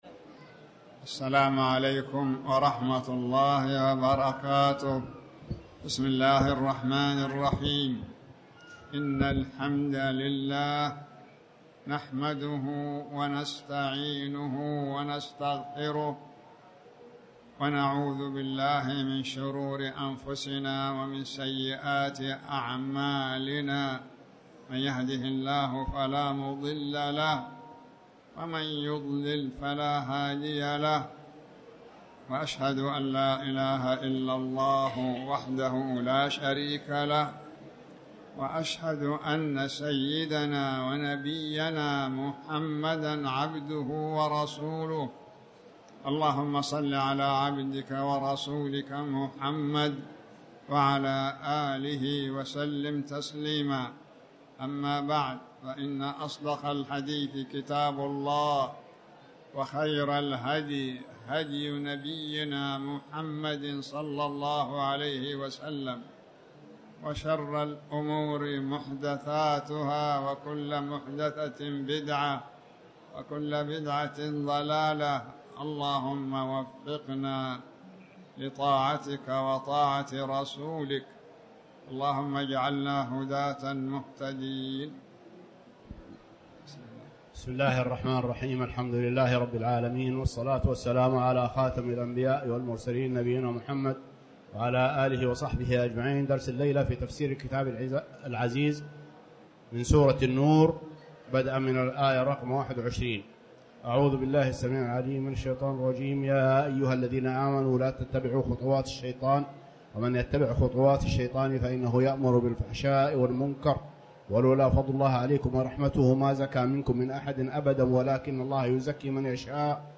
تاريخ النشر ٢٠ ربيع الأول ١٤٤٠ هـ المكان: المسجد الحرام الشيخ